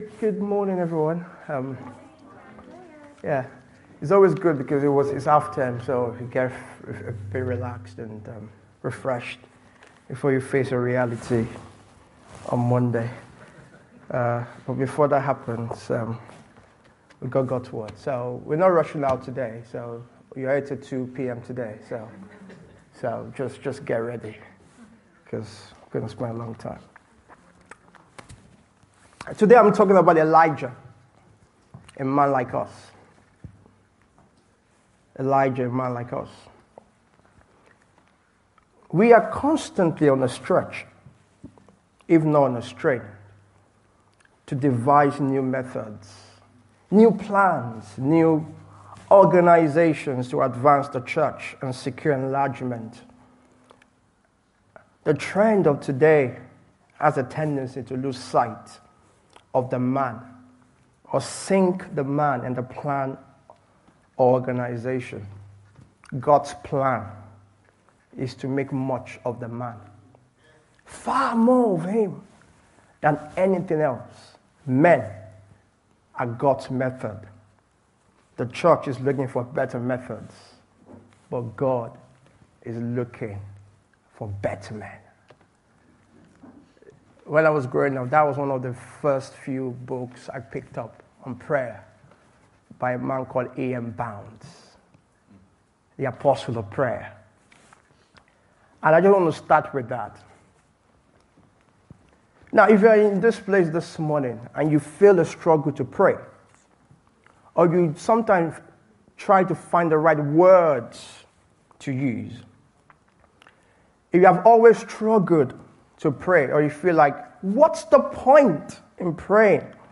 This sermon reminds us we can take great encouragement from the way God used Elijah.